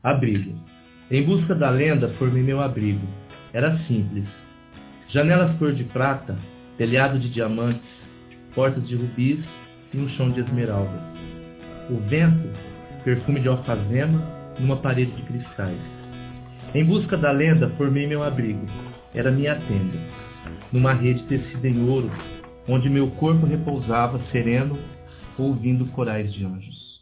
Nosso quadro Litincast apresenta podcasts de amigos e parceiros recitando poemas de diferentes estilos e autores.